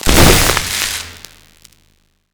electric_surge_blast_01.wav